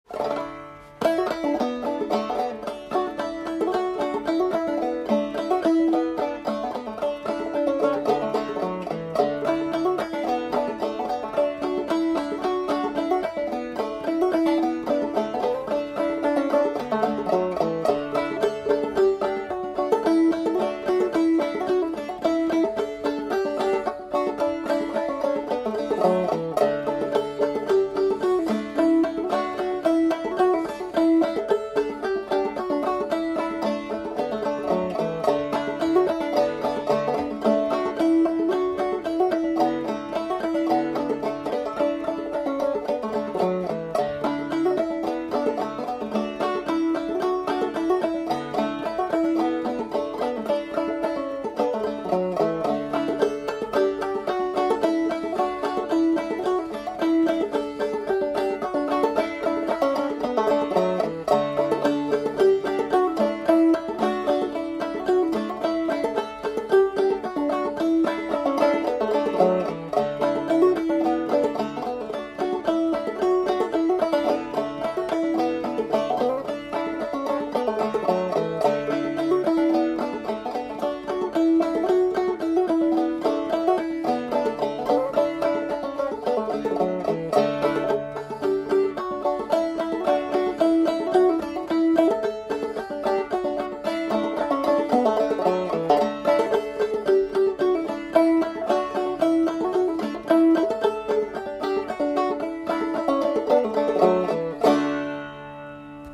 No Corn on Tygart – Banjo Hangout Newest 100 Clawhammer and Old-Time Songs – Podcast
No Corn on Tygart is a delightful tune with its minor chord in the A part and descending melody in the B part.